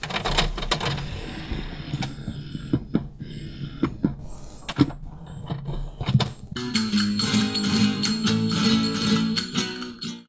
Salida y entrada de un carro del porta CDs de un reproductor